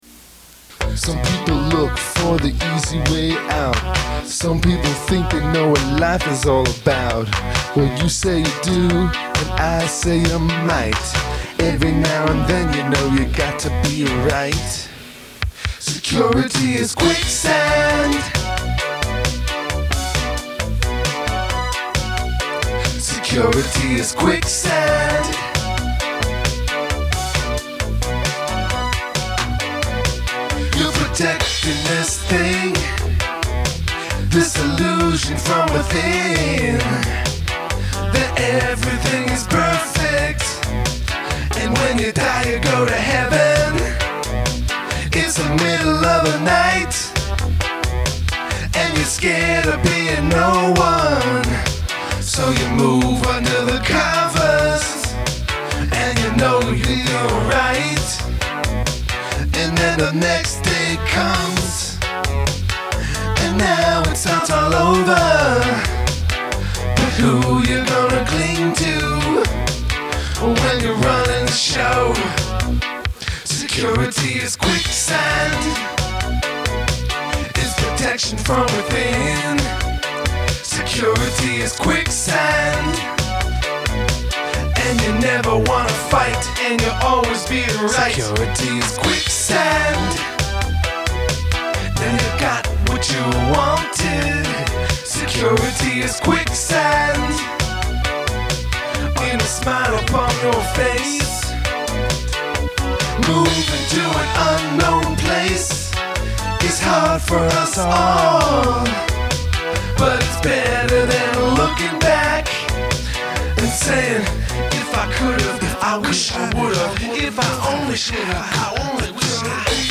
Audio | 80’s version